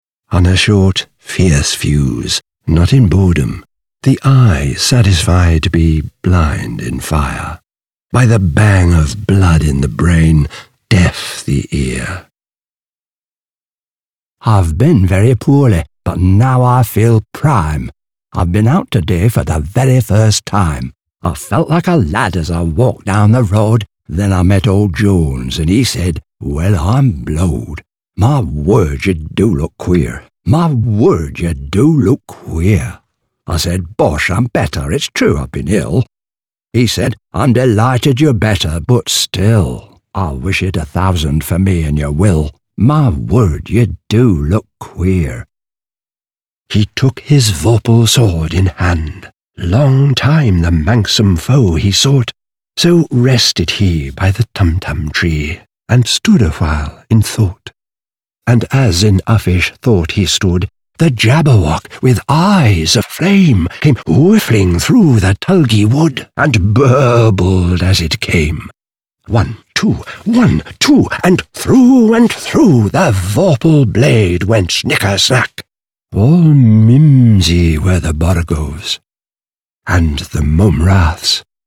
Storyteller with Characters
The storytellers here not only deliver an engaging narrative, they can also invoke a variety of characters bringing an audio book to life.
Accent: English